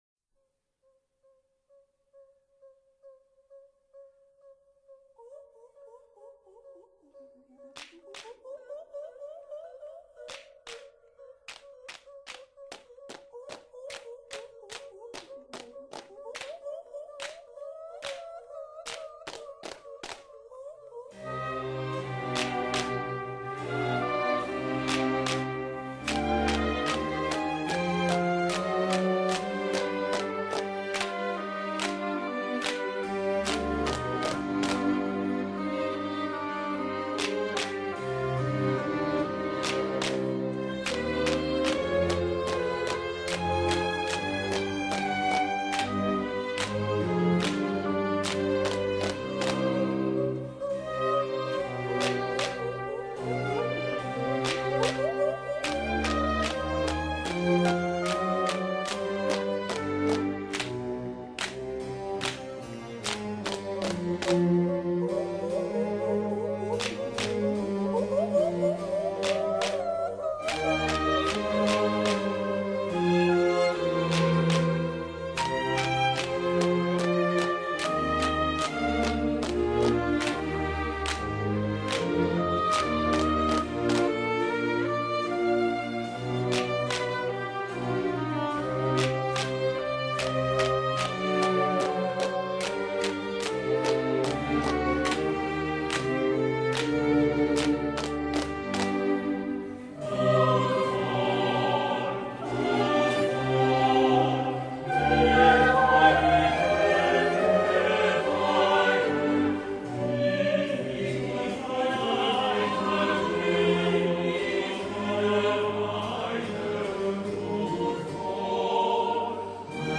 Musique   Variations de Jean Sebastien Bach